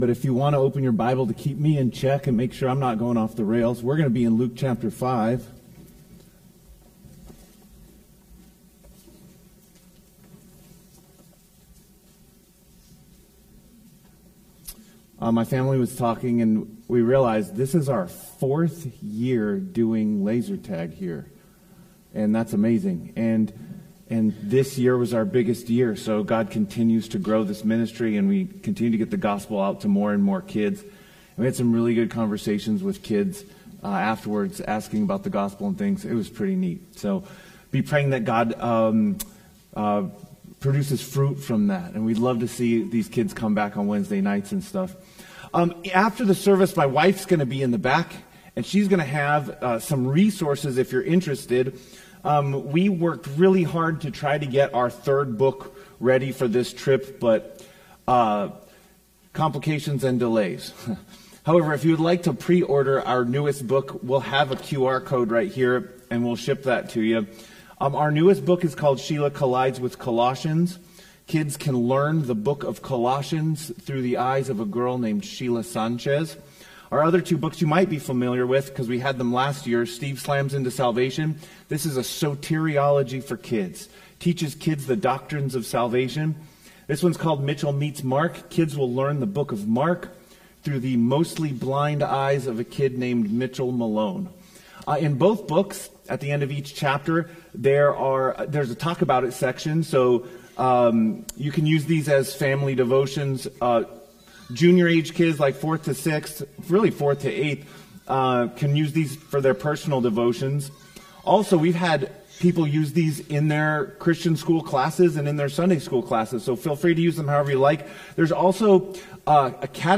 Sermons | Calvary Baptist Church
Single sermons that are not part of a series.